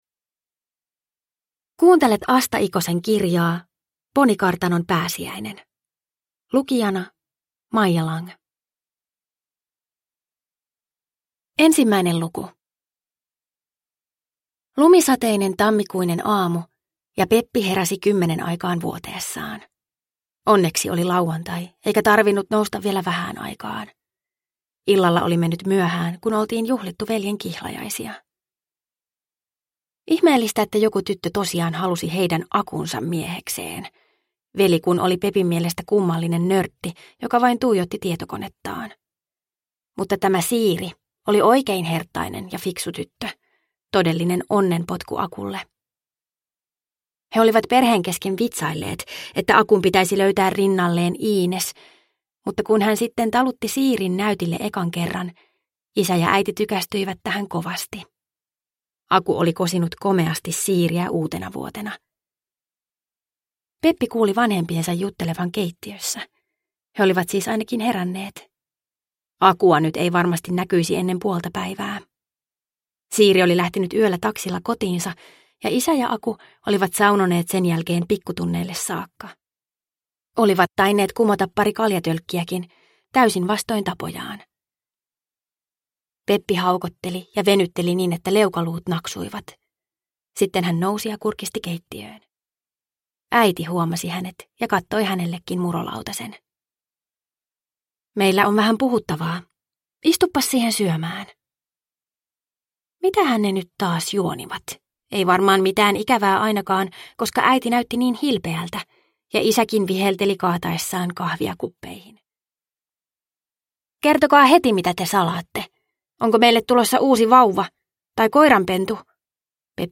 Ponikartanon pääsiäinen – Ljudbok – Laddas ner